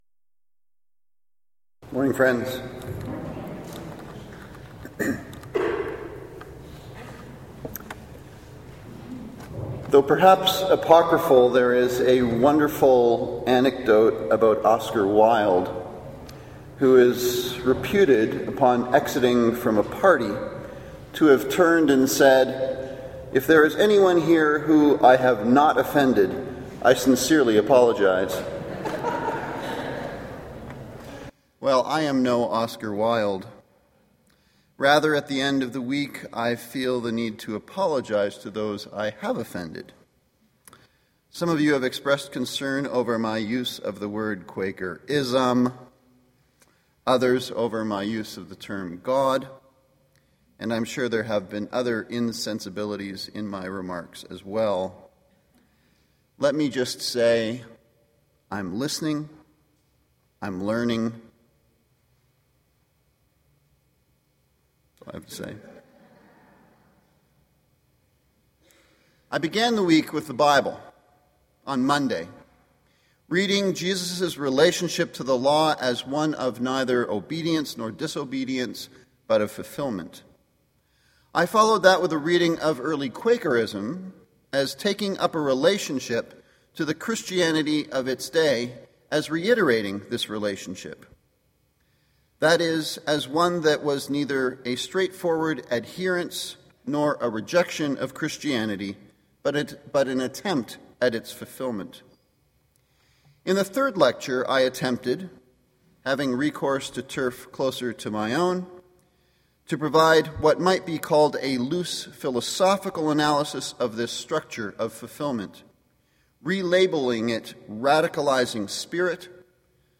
Canadian Yearly Meeting Bible Study, 2012, Augustana University, Camrose, Alberta
Lecture Five